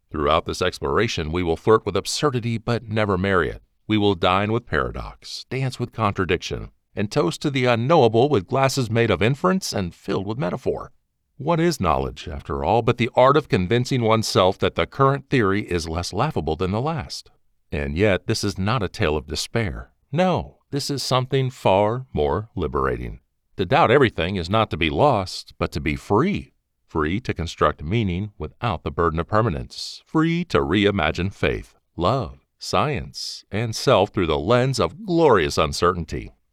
0618Philosophy_Audiobook_Sample.mp3